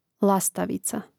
lȁstavica lastavica